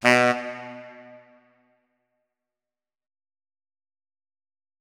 saxophone
notes-23.ogg